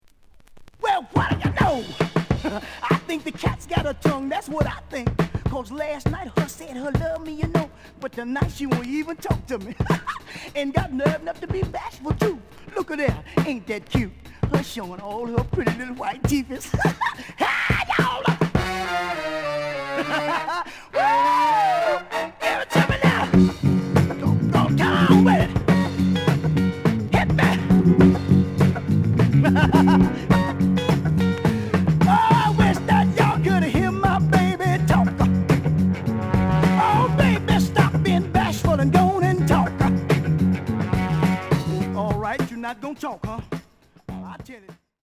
The audio sample is recorded from the actual item.
●Genre: Funk, 70's Funk
Writing on A side, but doesn't affect sound.